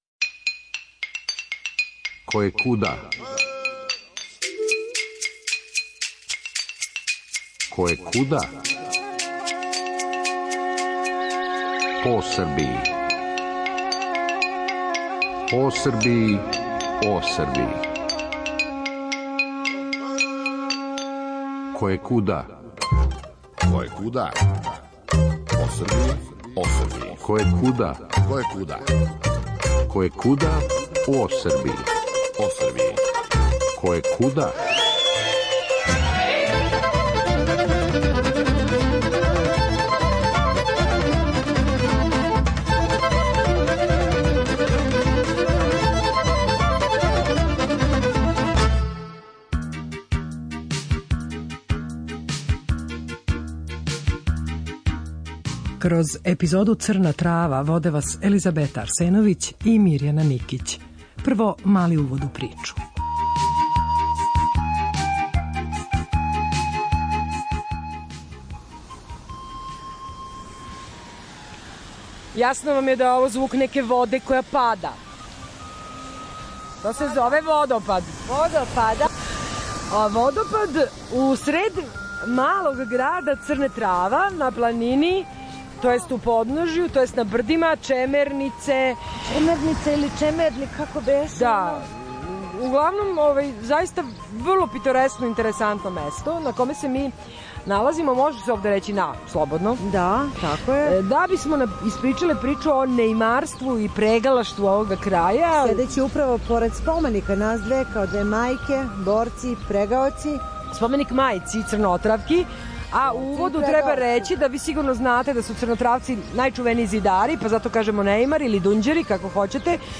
Посетиле смо летос овај планински градић на југоистоку Србије, током традиционалног Сабора печалбара и неимара, који се, већ годинама, одржава на дан Светог Прокопија, 21. јула.